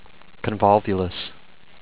con-vol-VU-lus